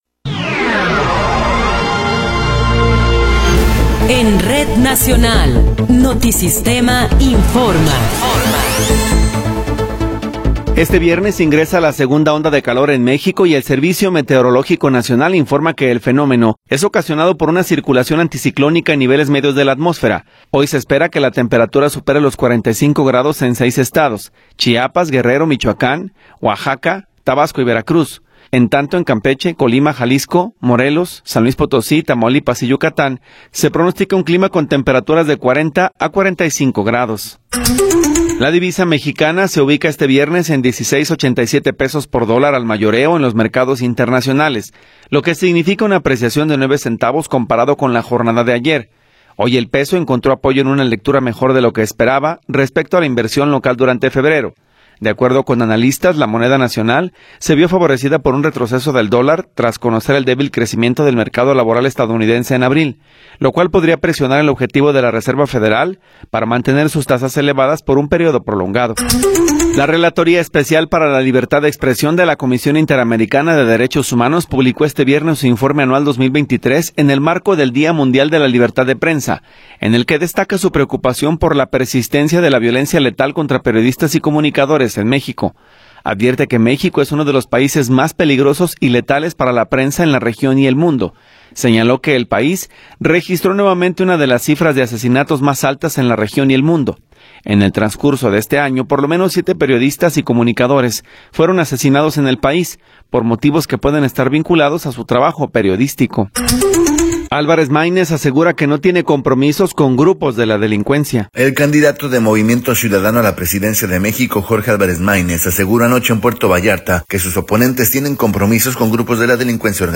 Noticiero 10 hrs. – 3 de Mayo de 2024
Resumen informativo Notisistema, la mejor y más completa información cada hora en la hora.